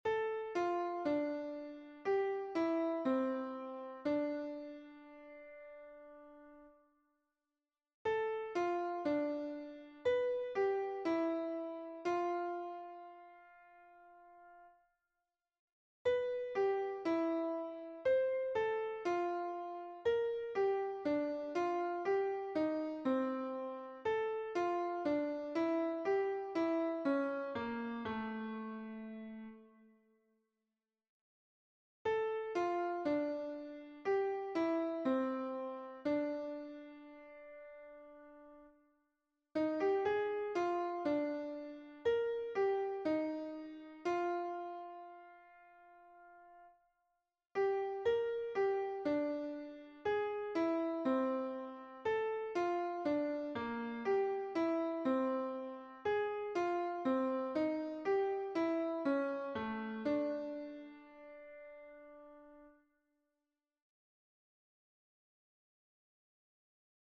It's a slow, slightly spooky melody in d dorian, though it fluctuates around & uses a lot of chords outside of the key.